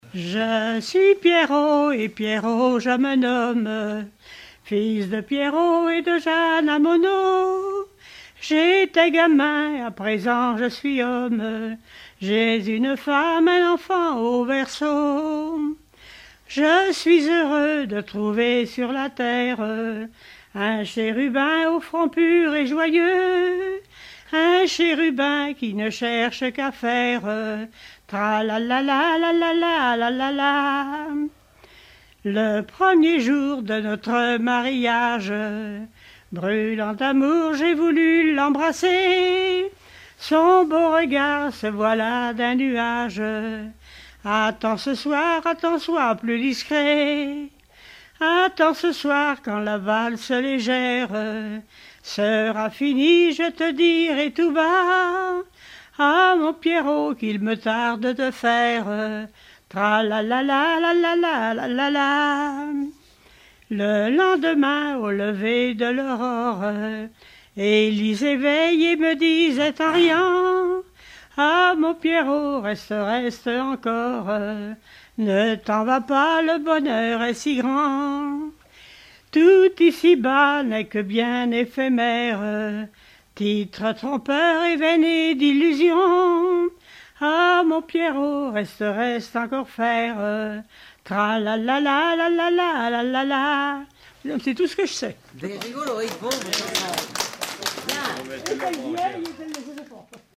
Genre strophique
Regroupement de chanteurs du canton
Pièce musicale inédite